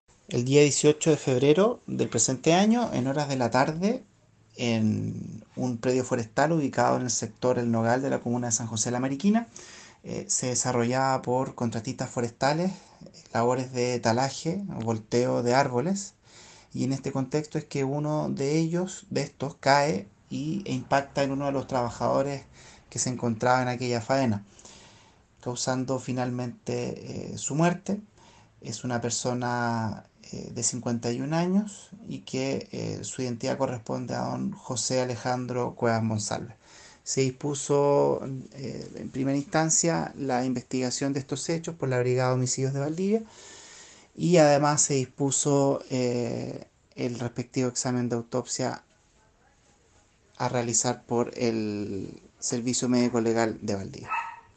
fiscal subrogante de Panguipulli, Sebastián Prokurica, sobre el fallecimiento de un hombre al que le cayó un árbol encima durante una faena forestal en la comuna de Mariquina